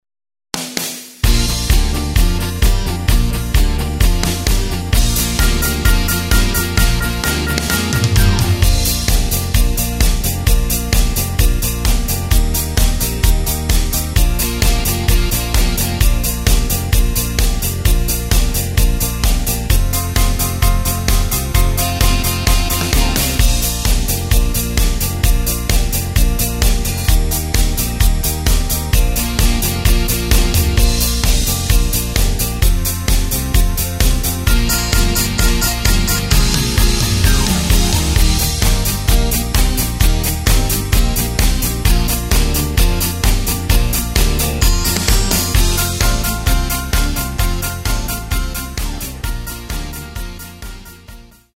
Takt:          4/4
Tempo:         130.00
Tonart:            C
Schlager aus dem Jahr 2006!
Playback mp3 Demo